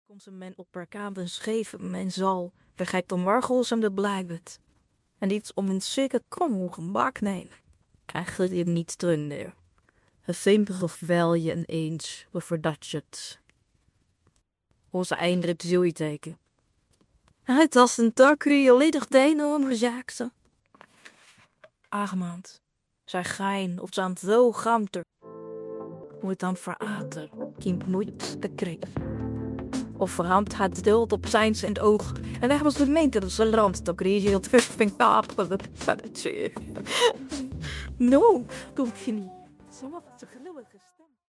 Toevallig kan een vriend lokaal op zijn computer stemmen klonen.